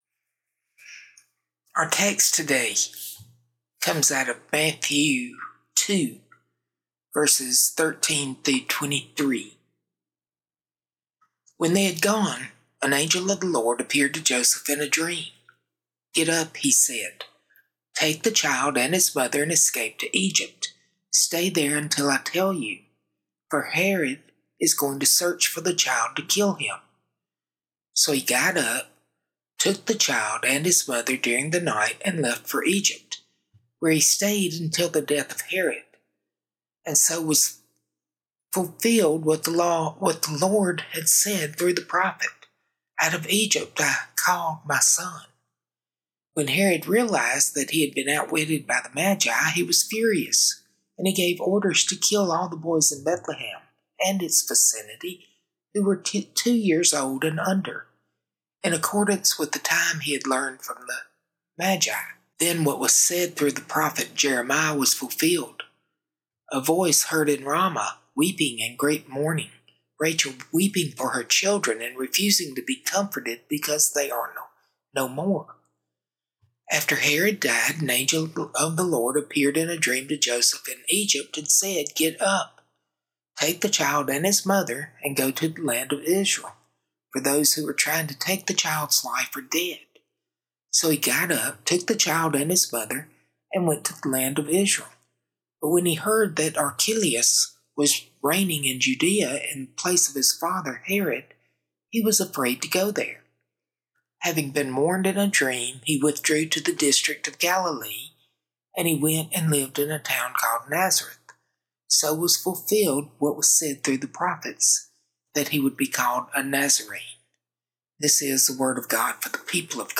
A Christmas sermon on Matthew 2:13–23 showing that Jesus entered danger, grief, and exile, revealing God with us in the hard places.